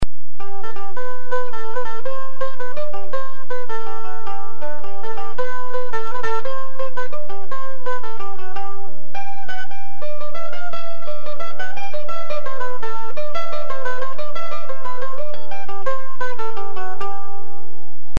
MANDOLIN
Mandolin Sound Clips